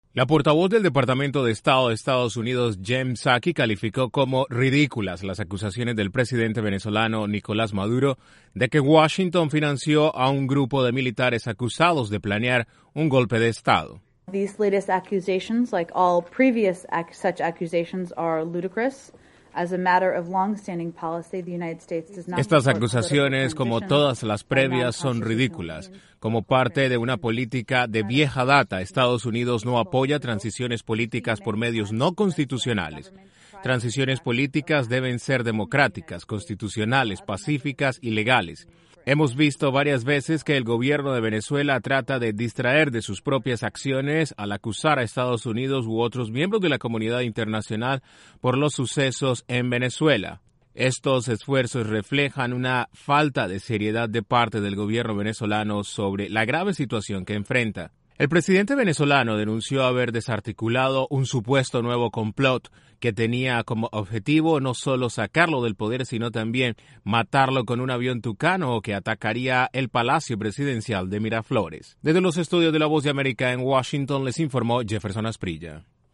Como una “ridiculez” calificó el Departamento de Estado las acusaciones del mandatario de Venezuela de que EEUU financió a un grupo de militares acusados de planear un golpe de Estado. Desde la Voz de América en Washington informa